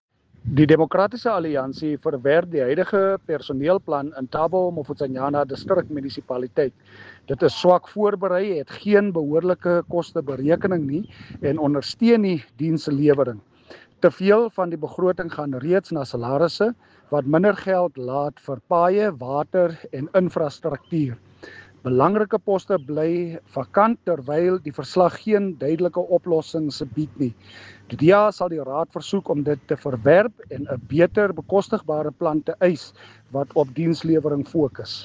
Afrikaans soundbite by Cllr Marius Marais